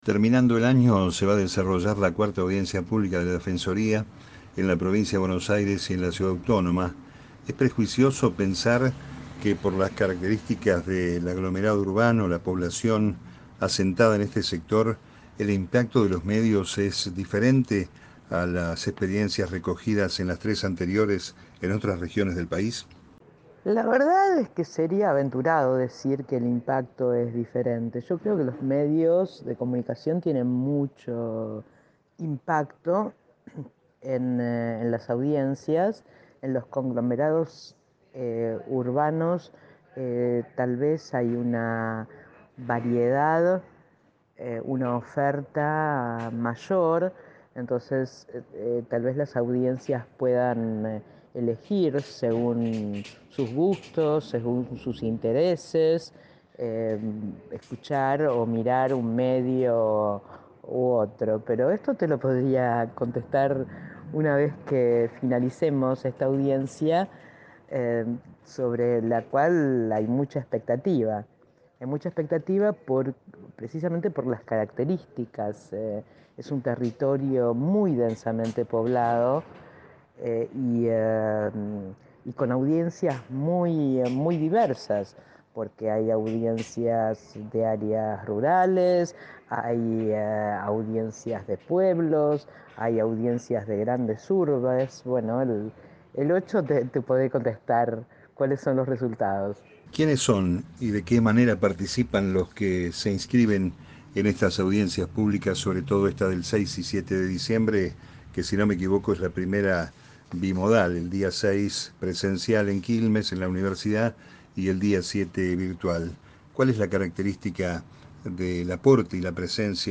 Entrevista a Miriam Lewin - 4ª Audiencia Pública 2021 Programa: Radio UNDAV